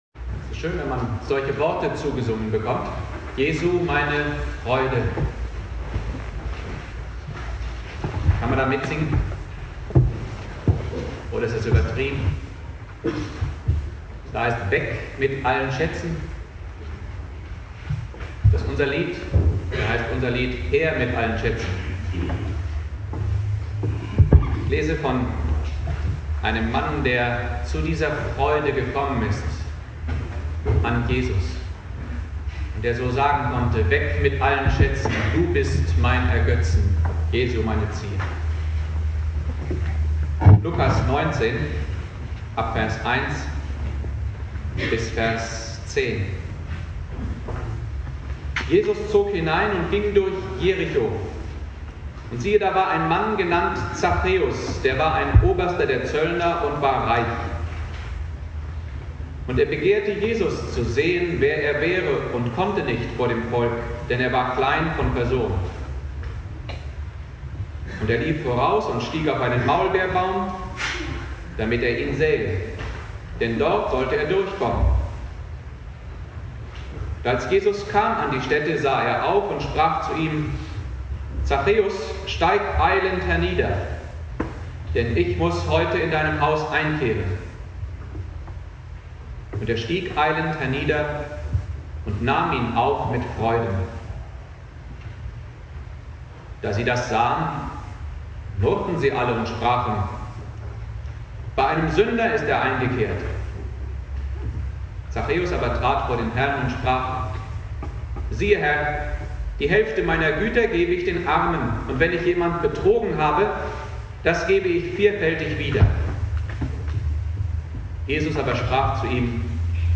Predigt
(bis 3.20 Min. mit Außenmikro aufgenommen) Bibeltext